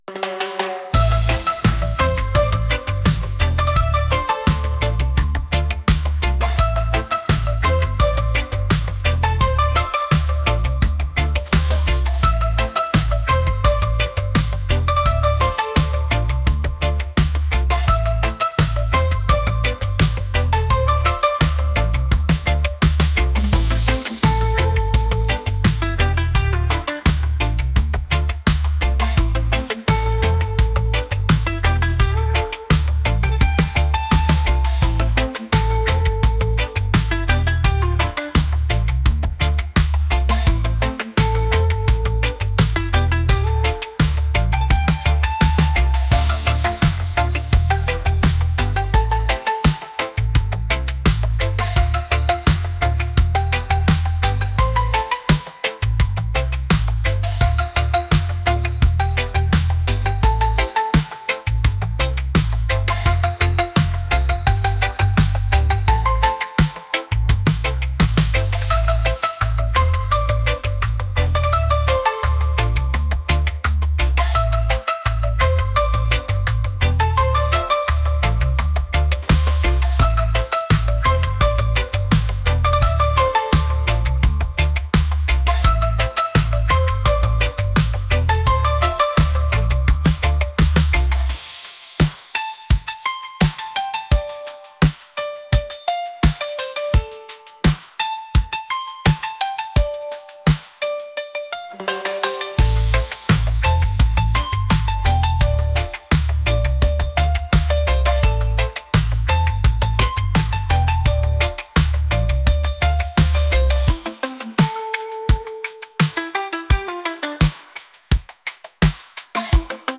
reggae | pop | latin